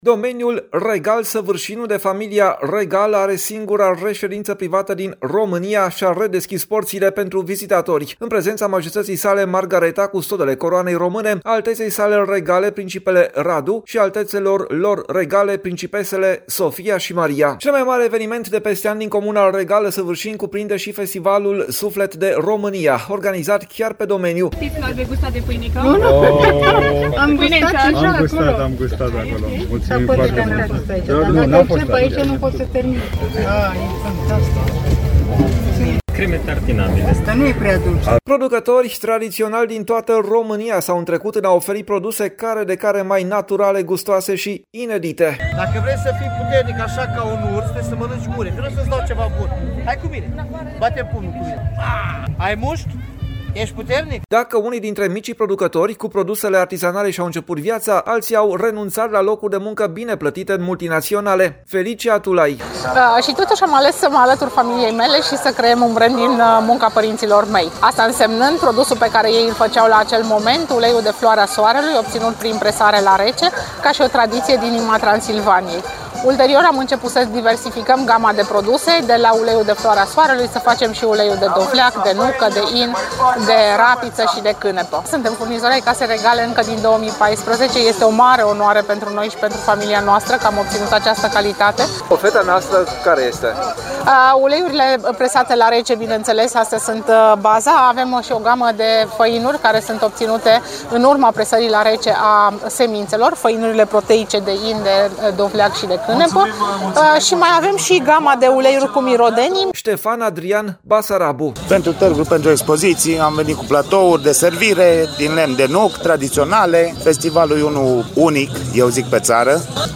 “Interesant de asta am și venit. Suntem de la Arad, apreciem și chiar e frumos ce am văzut”, spune un vizitator.
Sunt din Timișoara și cred că e în regulă ca oamenii să știe că avem astfel de domenii în țară”, spune o tânără.
De la Timișoara am venit, o gașcă întreagă de mame cu copii. E super. N-am mai fost până acum”, spune o vizitatoare.